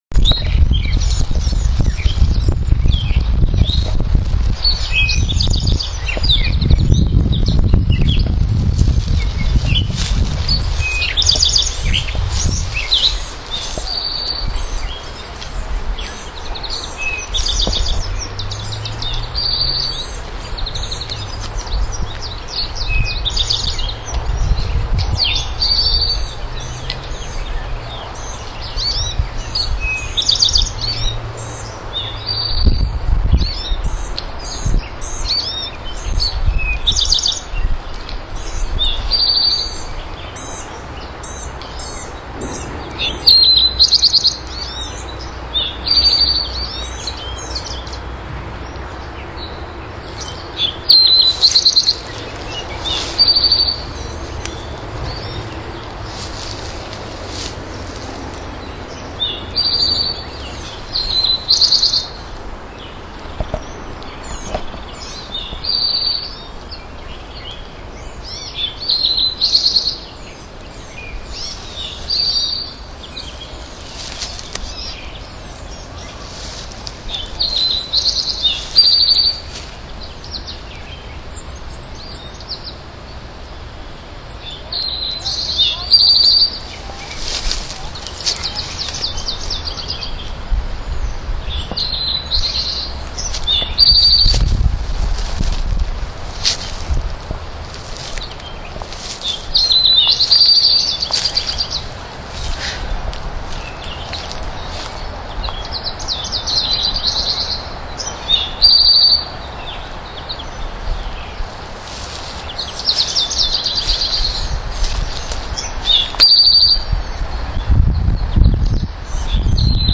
StaffordBirds.mp3